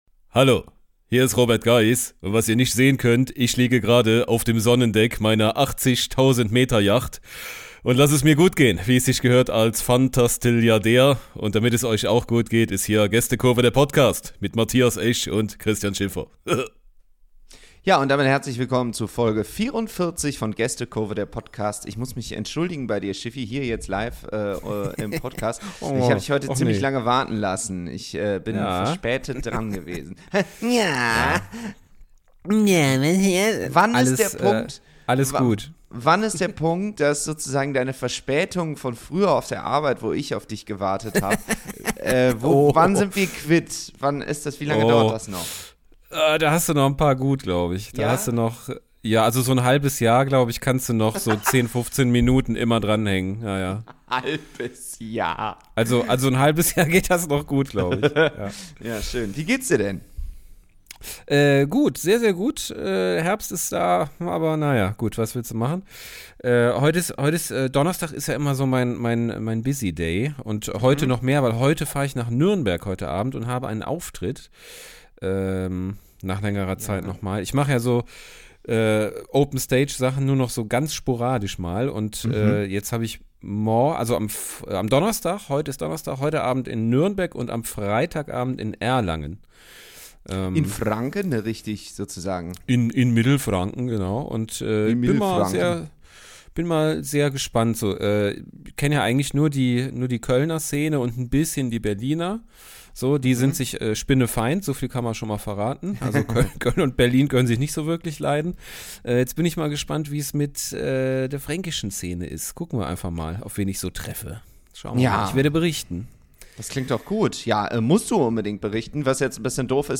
Ab sofort tun sie das nicht mehr nur zu zweit, sondern jede Woche mit einem Gast oder einer Gästin.